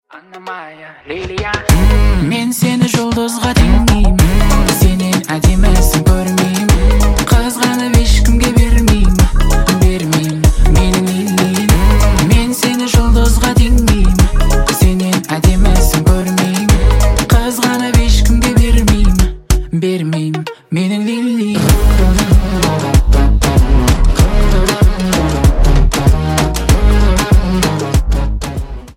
2024 » Новинки » Казахские » Поп Скачать припев